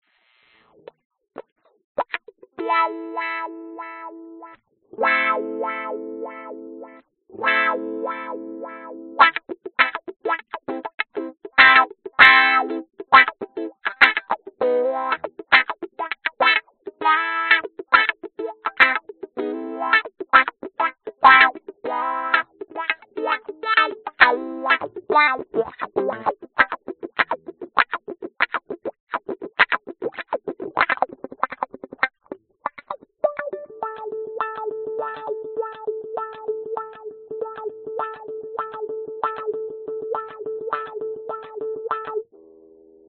吉他弹奏
描述：吉他上的所有琴弦都会立刻弹奏。使用Tascam DR07 Mk.II麦克风录制。
Tag: 乱弹 声学 吉他 弦声 弦乐